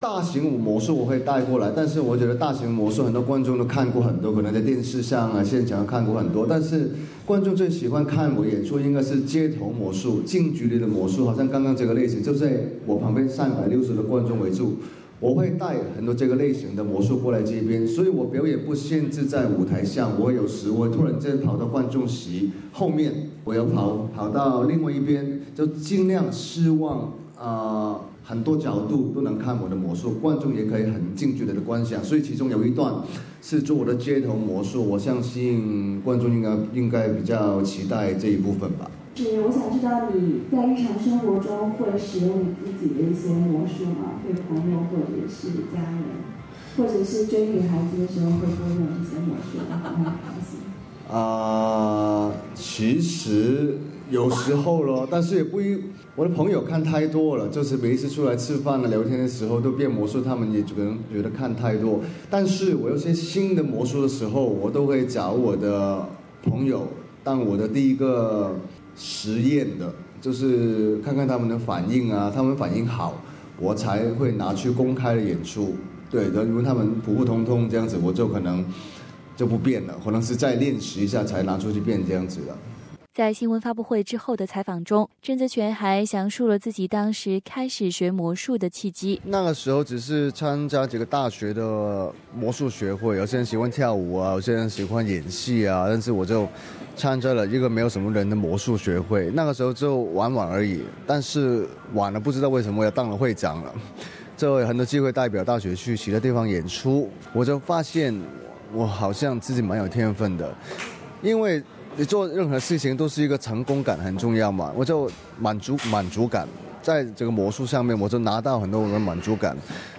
在新聞髮布會上